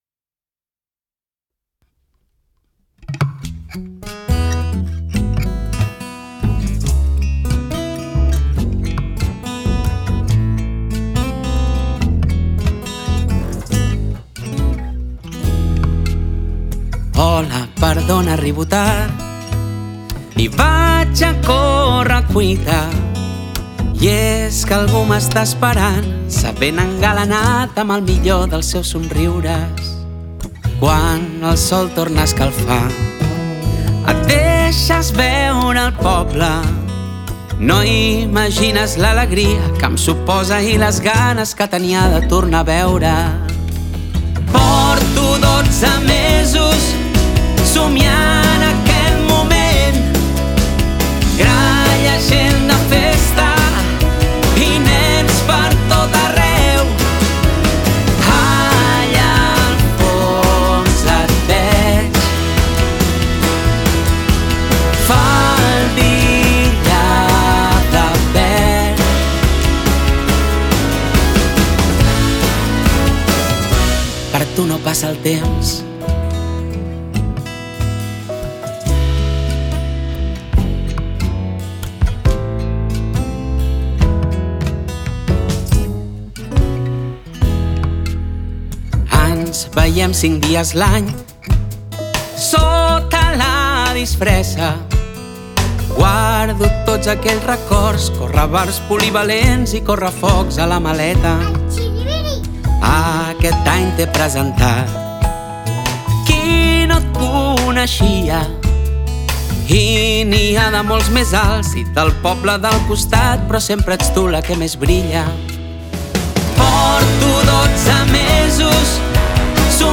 Durant la presentació de la programació
que l’ha interpretada en directe per al públic assistent.